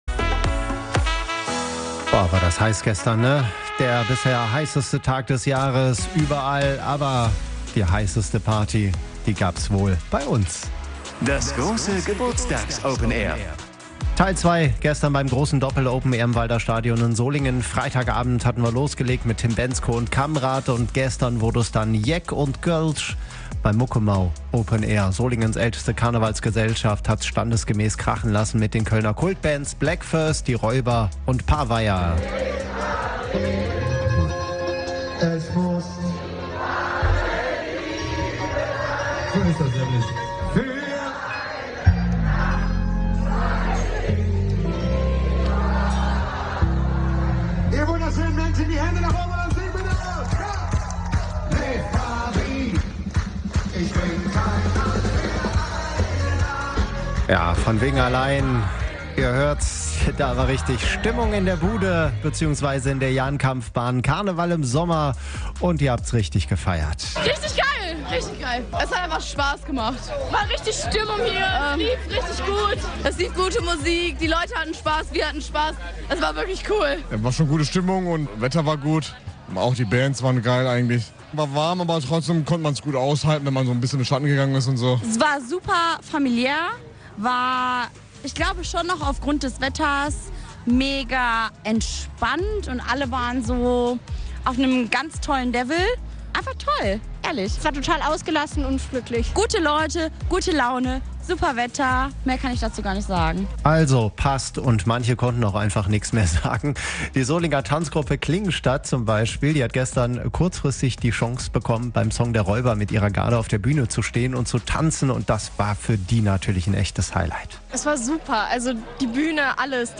Karneval im Sommer: Die Kölner Kultbands Räuber, Paveier und Bläck Fööss sorgten beim Muckemau Open Air im Walder Stadion in Solingen für ausgelassene Stimmung bei hochsommerlichen Temperaturen.